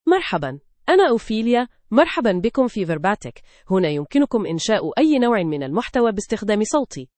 FemaleArabic (Standard)
OpheliaFemale Arabic AI voice
Ophelia is a female AI voice for Arabic (Standard).
Voice sample
Listen to Ophelia's female Arabic voice.
Ophelia delivers clear pronunciation with authentic Standard Arabic intonation, making your content sound professionally produced.